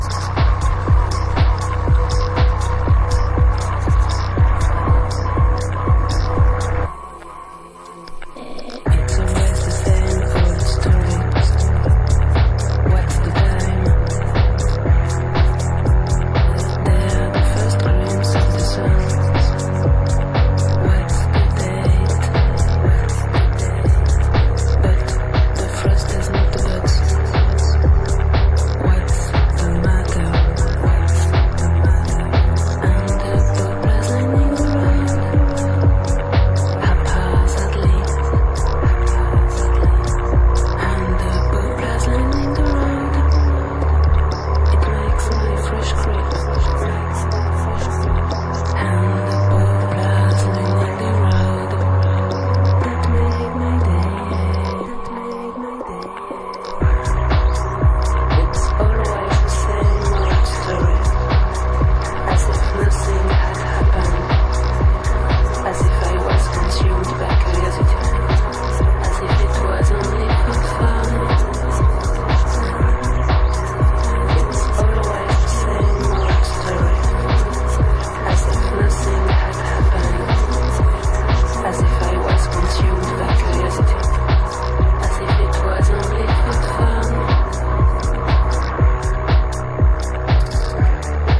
abstract ambient soundscapes
rock and electronic pop songs with melodies.
a shouter.
Electronix